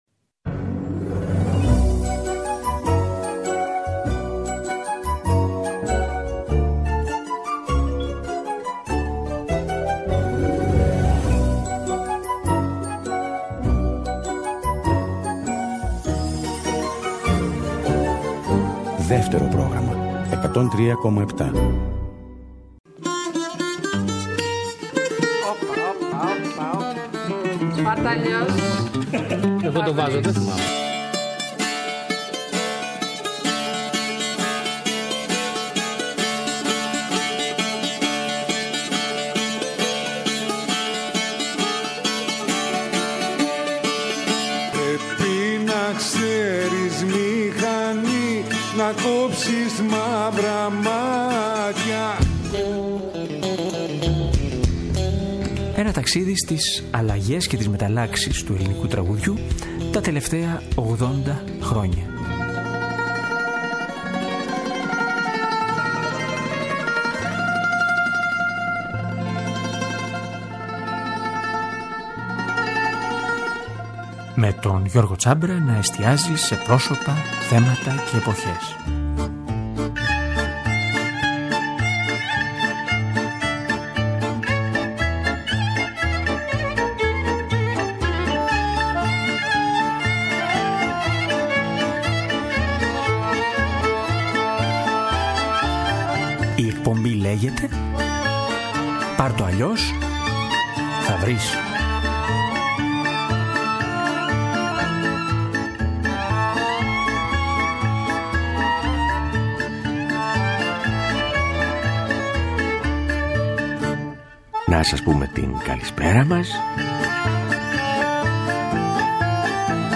Ποιες από αυτές τις εποχές, ποιες από αυτές τις επιλογές «αντέχουν» σήμερα περισσότερο στα ακούσματα και στις ευαισθησίες μας; Η εκπομπή δεν απαντά στο ερώτημα. Απλά, το θέτει μέσα από τις επιλογές της και μέσα από αποσπάσματα από συζητήσεις μαζί του, στα νεώτερα χρόνια.